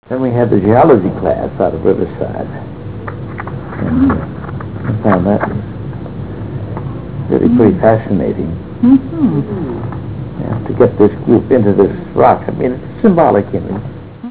115Kb Ulaw Soundfile Hear Ansel Adams discuss this photo: [115Kb Ulaw Soundfile]